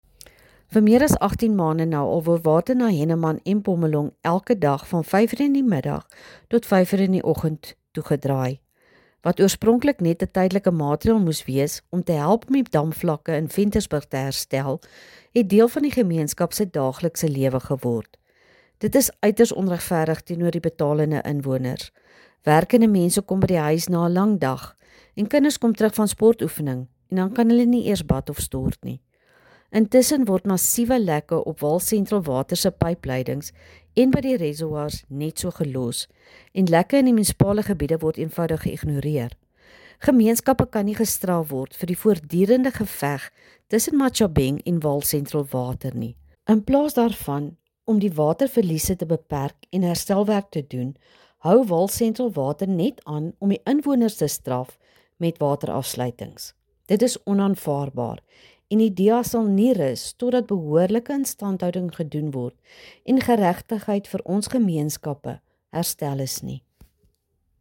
Afrikaans soundbites by Cllr Maxie Badenhorst and